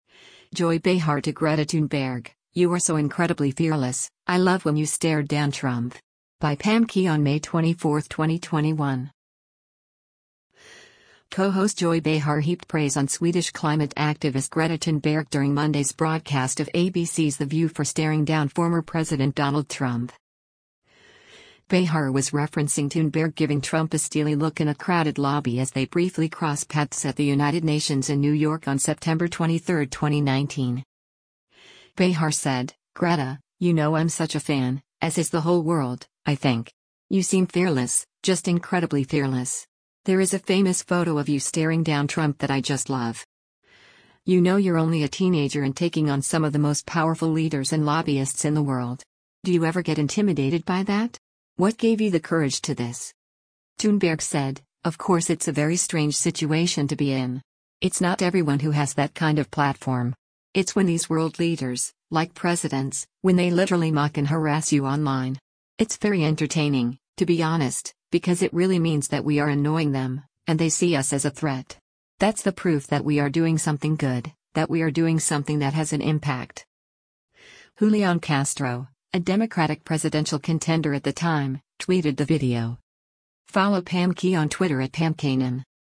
Co-host Joy Behar heaped praise on Swedish climate activist Greta Thunberg during Monday’s broadcast of ABC’s “The View” for “staring down” former President Donald Trump.